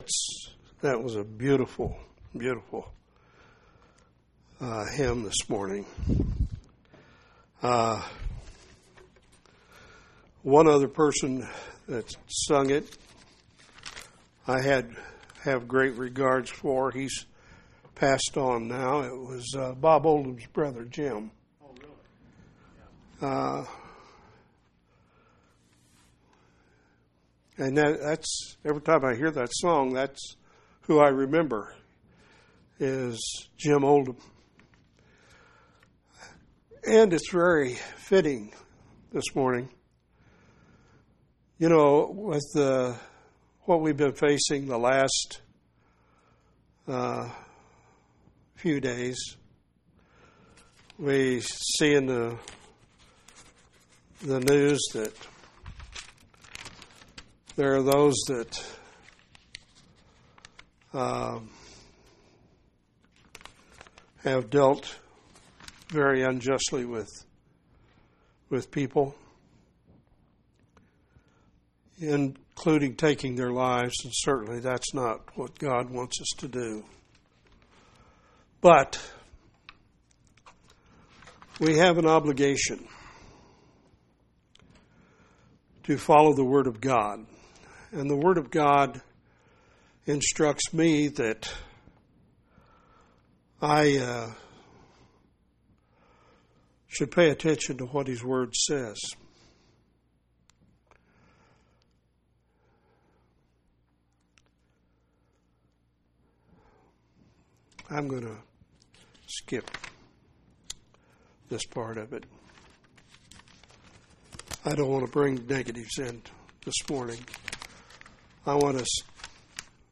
7/10/2016 Location: Collins Local Event